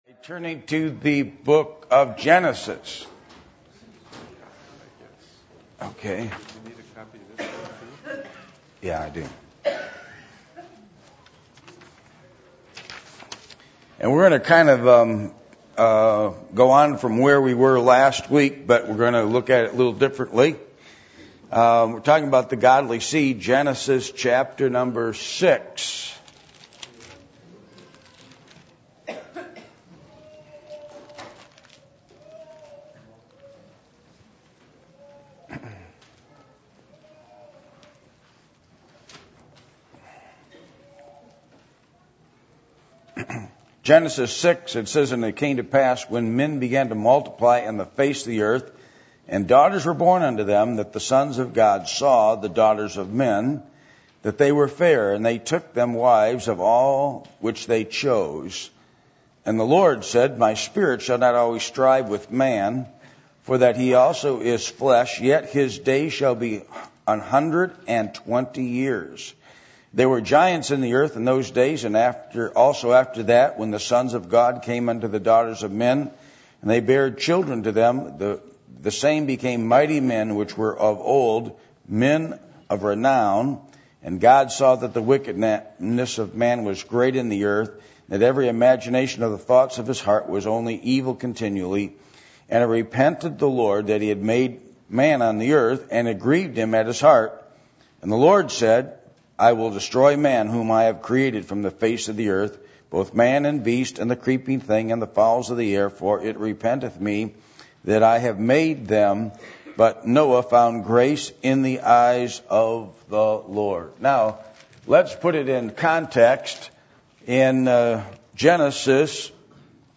Genesis 6:1-8 Service Type: Adult Sunday School %todo_render% « Selfishness